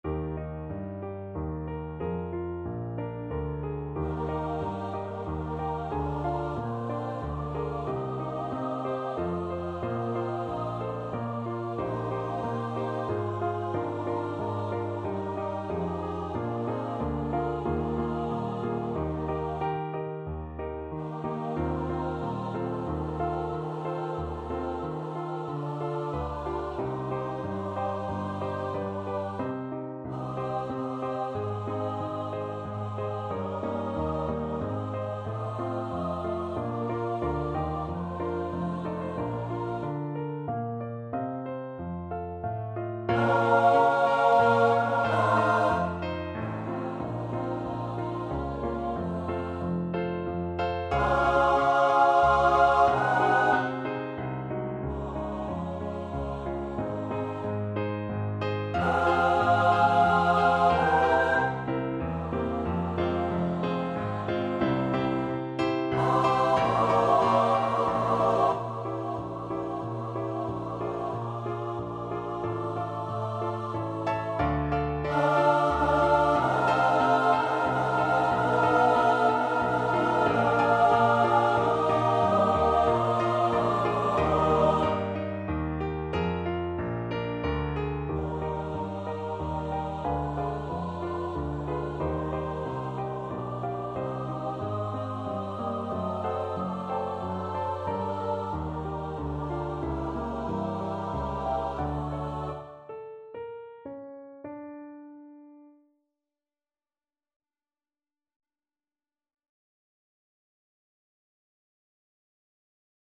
Free Sheet music for Choir
~ = 92 Larghetto
Eb major (Sounding Pitch) (View more Eb major Music for Choir )
3/4 (View more 3/4 Music)
Classical (View more Classical Choir Music)